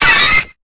Cri de Pichu dans Pokémon Épée et Bouclier.